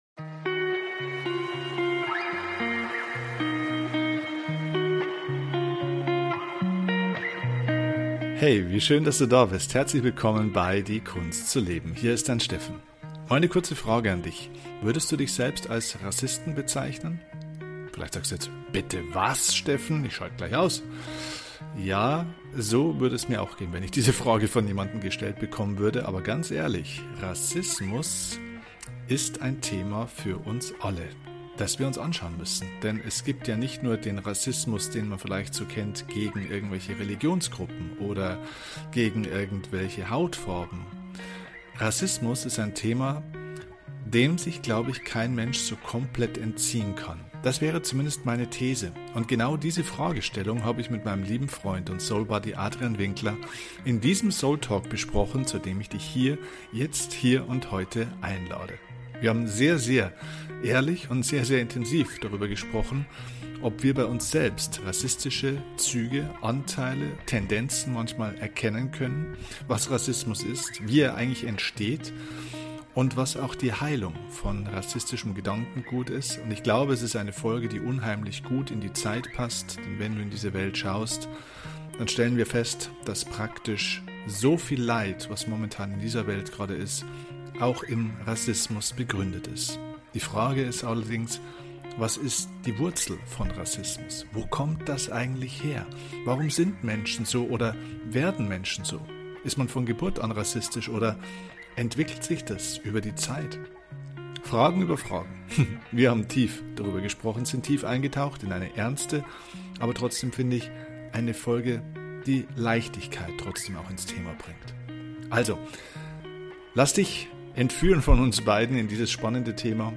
Dieses wichtige Thema, das uns alle betrifft besprechen wir in dieser Folge SOUL TALK. Wie immer bei diesem Format: Es gibt kein Skript, nur Gedanken zweier Freunde.